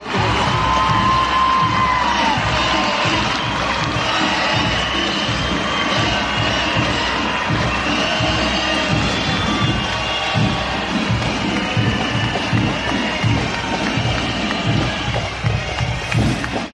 Gritos de ¡Meryl, Meryl! a la llegada de la actriz al Teatro Campoamor